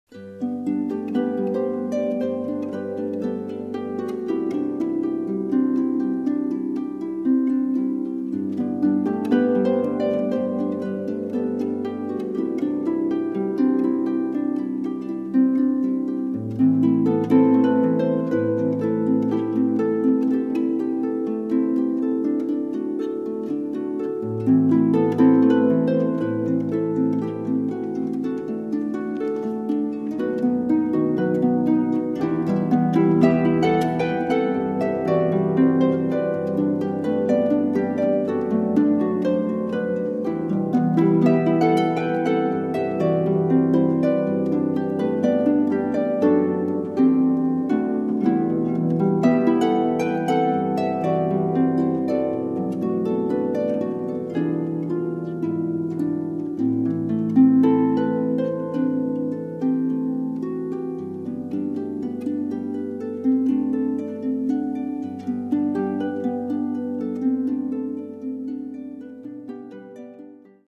Wedding Harpist
harp arrangements